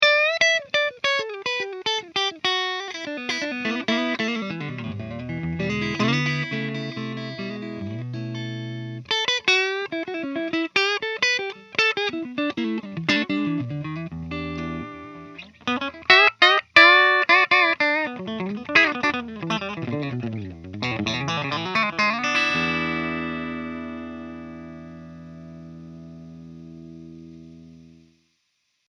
Country riff 2